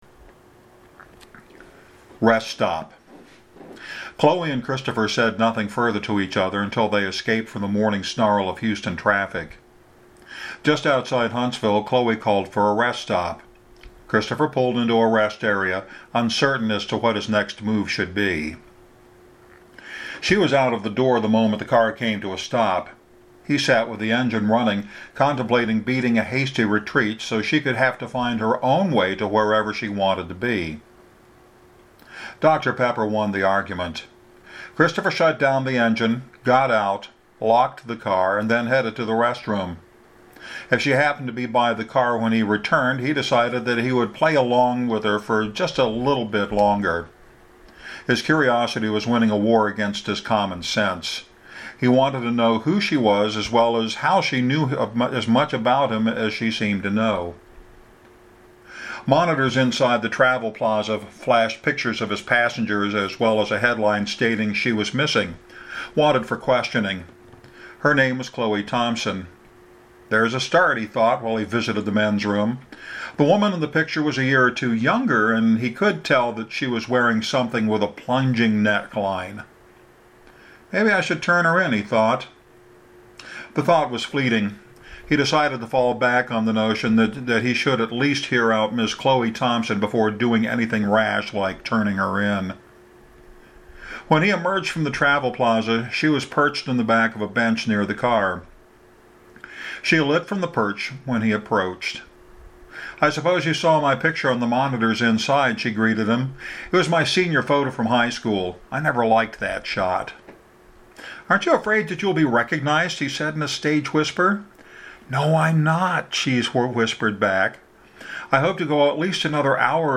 You’ll hear just a little of my bumbling and fumbling around (this is raw audio, by the way) while we take a bathroom break with Chloe and Christopher. To this point, he has no idea who she is, even after seeing her picture being plastered on the monitors inside the rest stop.